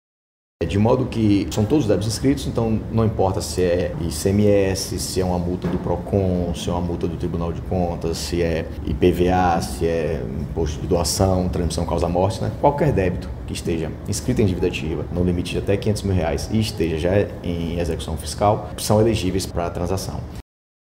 A PGE/AM está disponibilizando propostas de descontos em juros e multas para débitos de até R$ 500 mil. Condições diferenciadas, como isenção de 100% dos juros, corrigindo o valor original do débito pelo Índice de Preços ao Consumidor Amplo (IPCA); descontos progressivos de até 65% do valor da multa fazem parte da ação, além de parcelamento em até 60 vezes; como destaca o subprocurador-geral Adjunto da PGE-AM, Eugênio Nunes Silva.